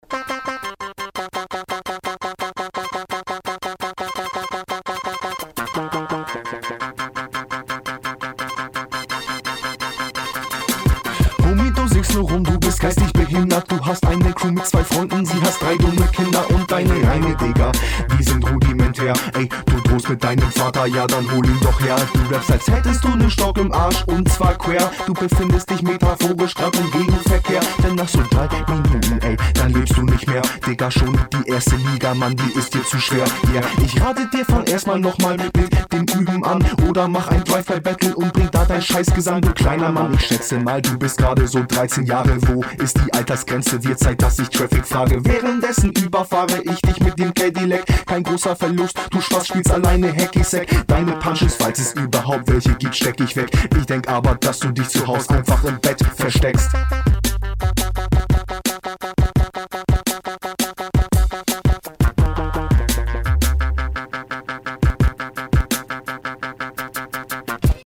Bro dieser Beat tut niemandem gut.
Mega interessanter Beat. Und auch der Flow dazu am Anfang der Zeile geil.